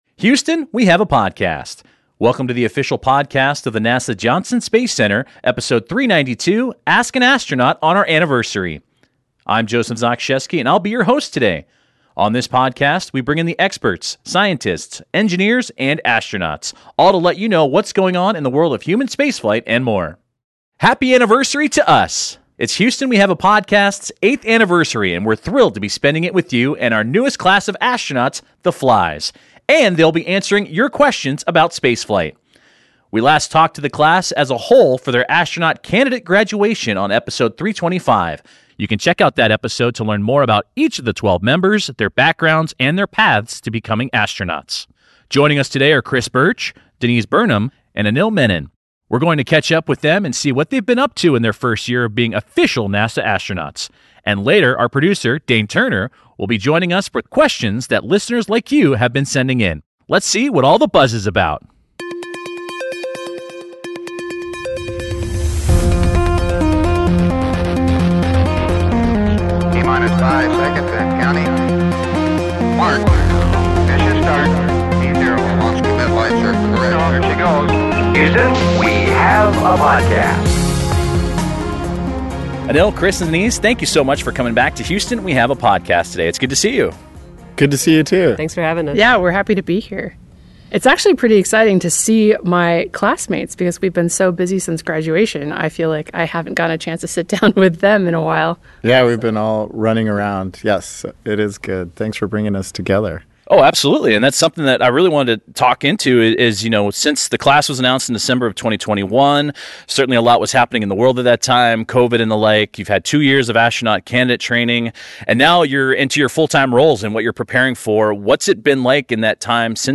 On episode 392, three astronauts from the agency’s most recent class discuss their first year of training and answer questions from our listeners.